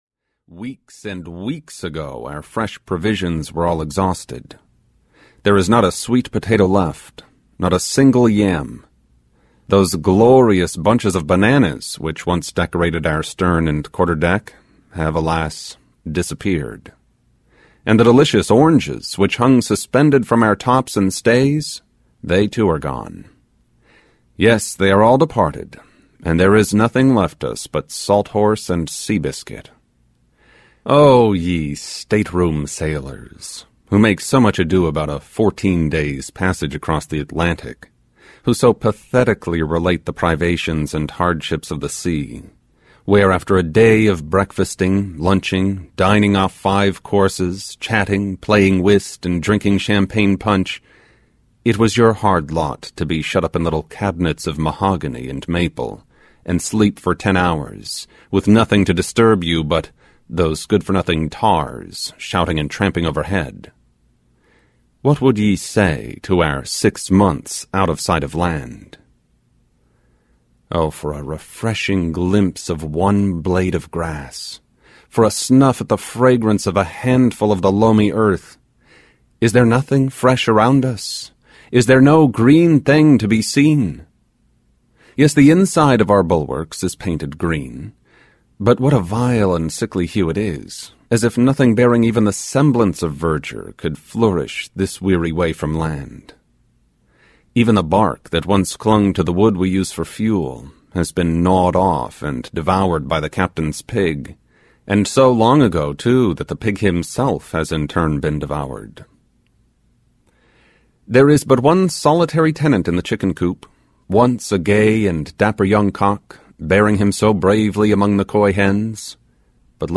Typee (EN) audiokniha
Ukázka z knihy